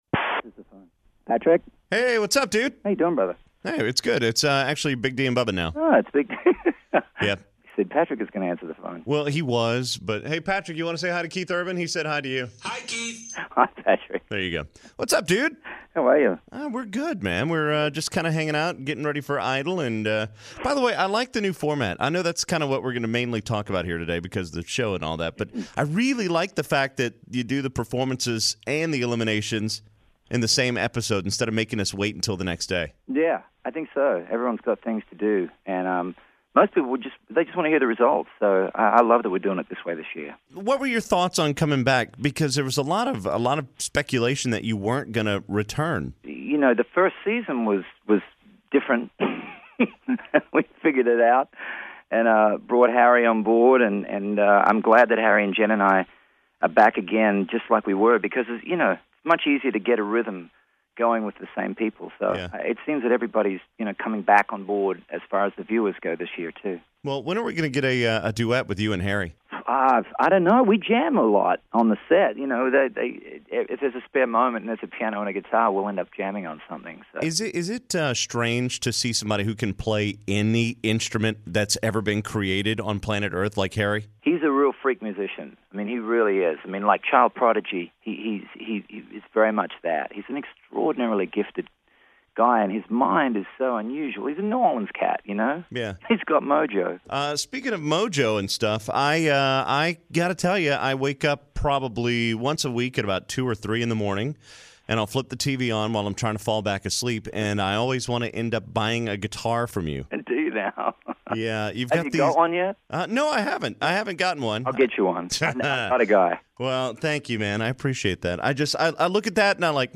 Keith Urban interview 01/28/2015